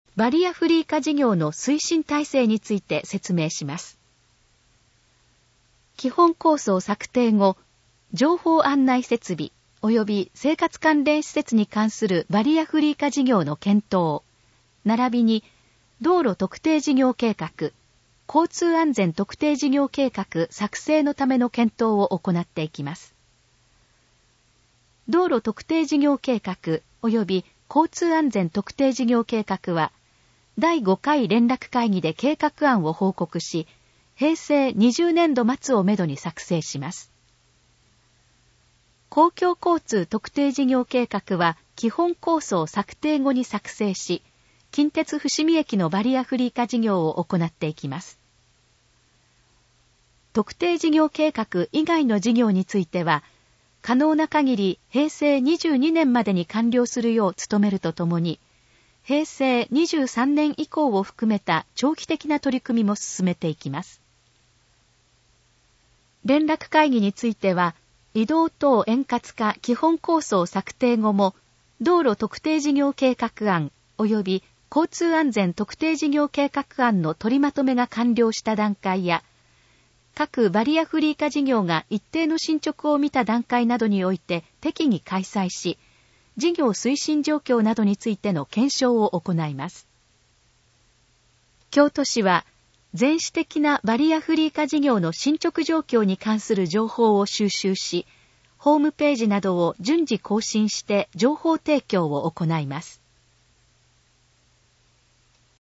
このページの要約を音声で読み上げます。
ナレーション再生 約413KB